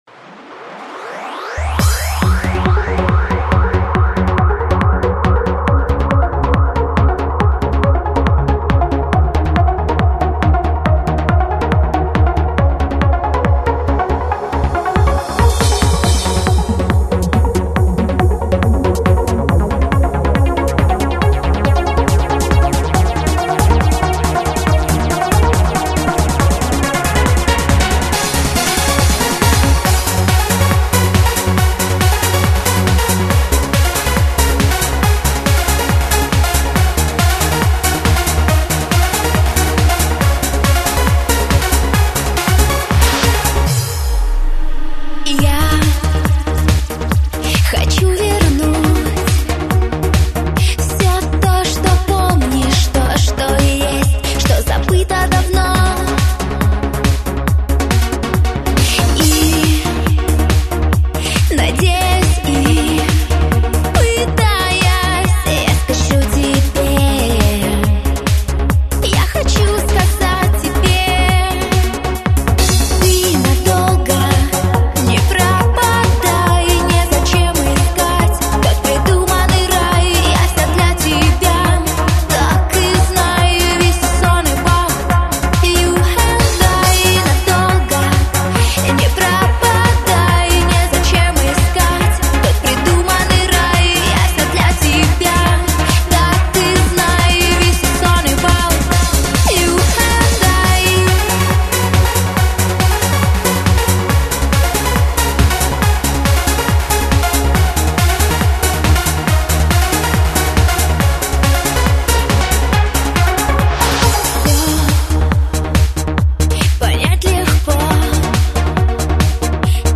Жанр:Vocal-Trance